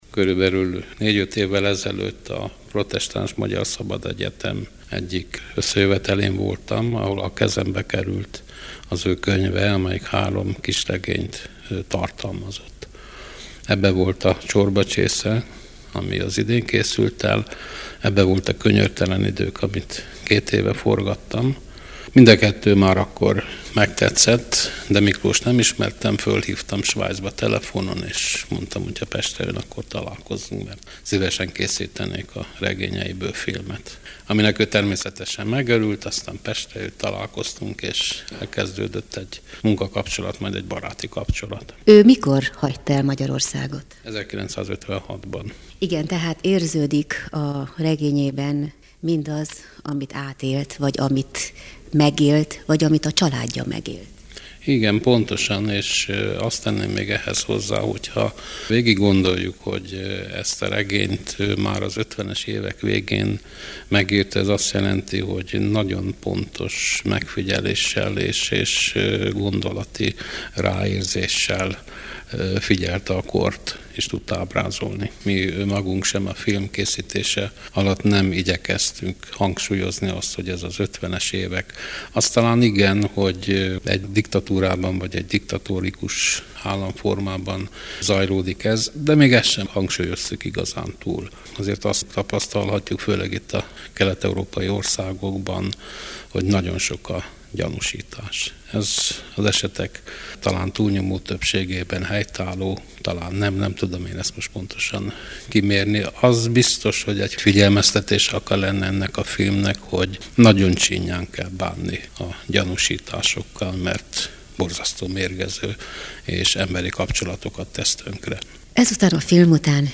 Mi egy olyan beszélgetés-részlettel emlékezünk rá, amely 1993-ban készült, a Vigyázók című filmje a Magyar Filmszemlén volt látható, és itt, Marosvásárhelyen is vetítették.